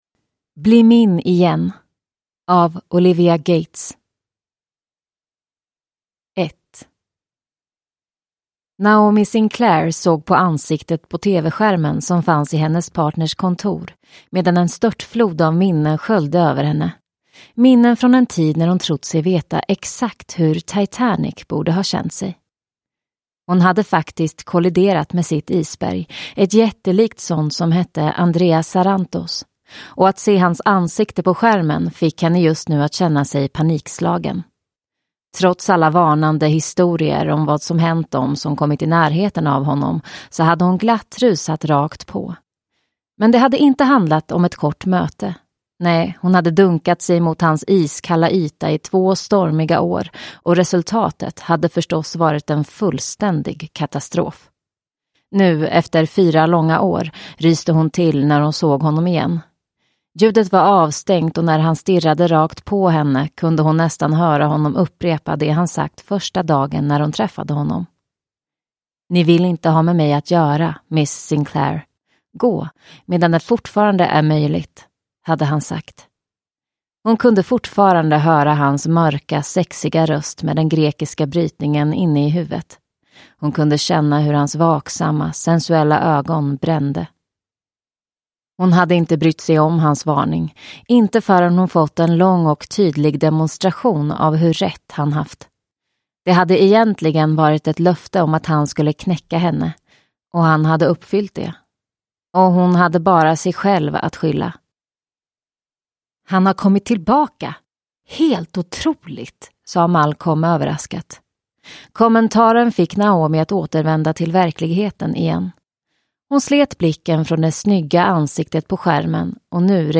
Bli min igen – Ljudbok